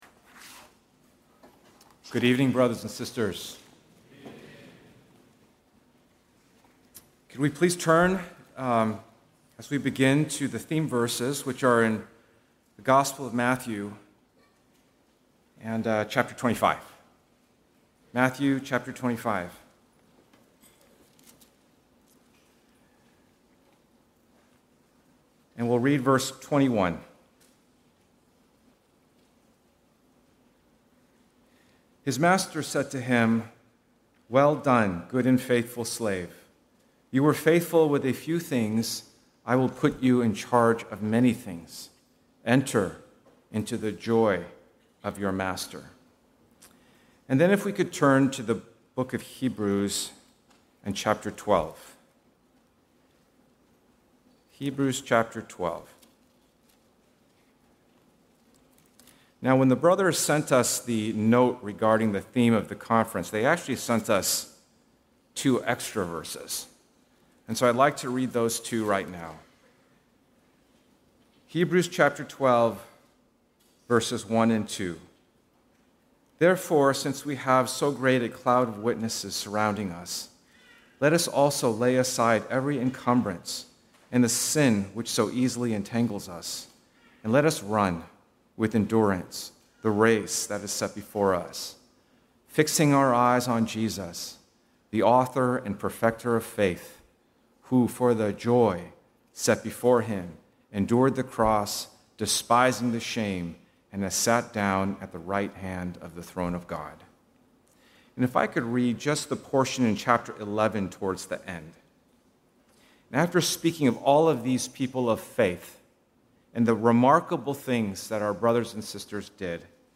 A collection of Christ focused messages published by the Christian Testimony Ministry in Richmond, VA.
Harvey Cedars Conference